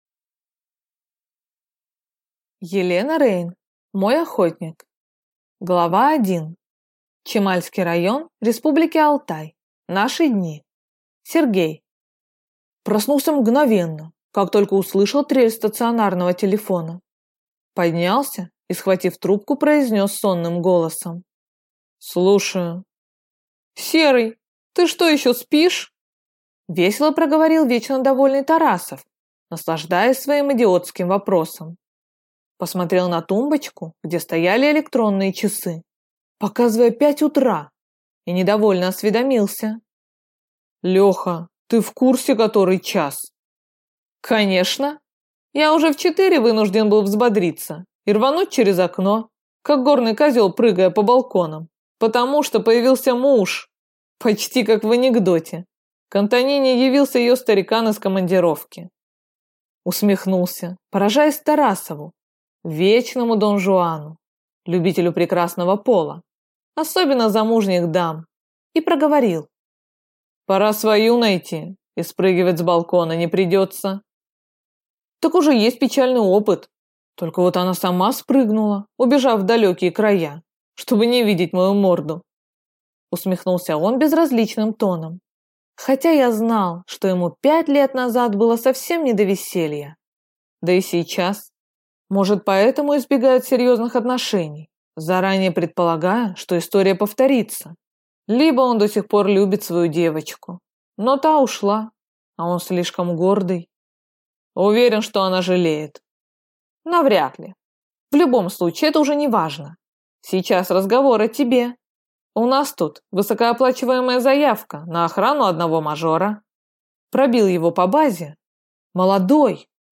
Аудиокнига Мой Охотник | Библиотека аудиокниг